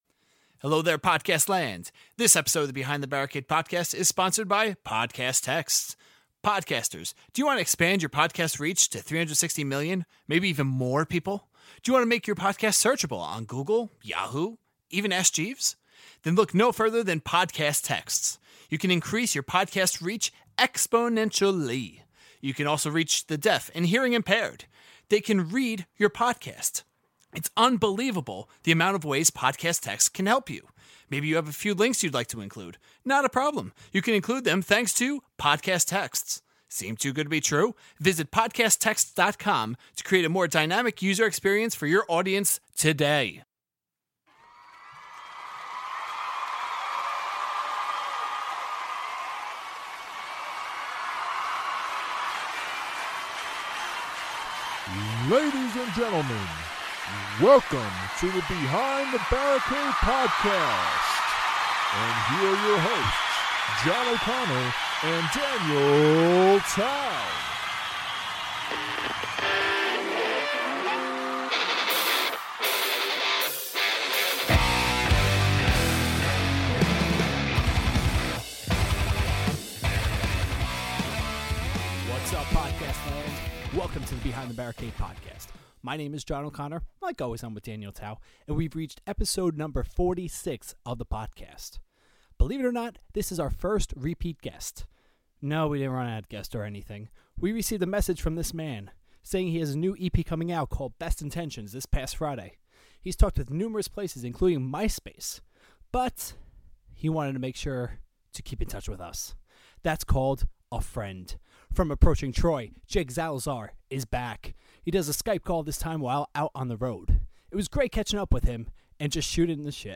Pre-Interview Song: Wake Up Post-Interview Song: Sparta (This Isn’t Over)